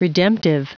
Prononciation du mot redemptive en anglais (fichier audio)
Prononciation du mot : redemptive